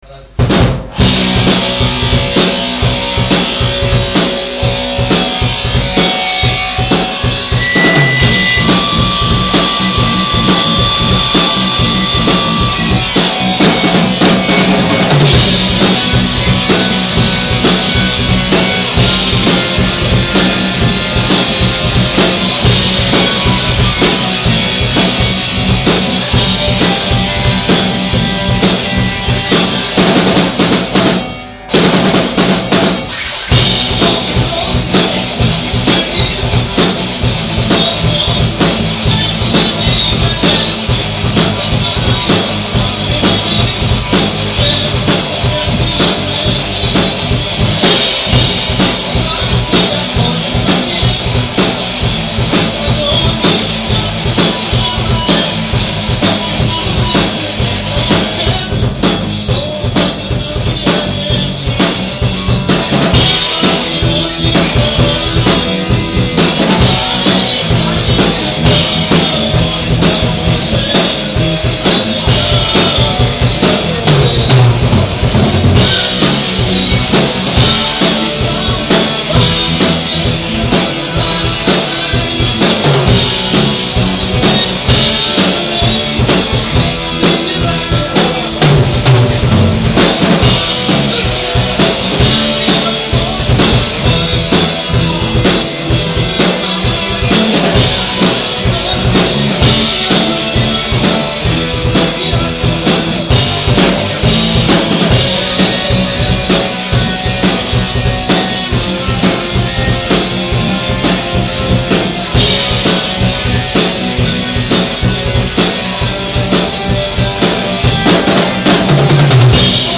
Запись с репетиций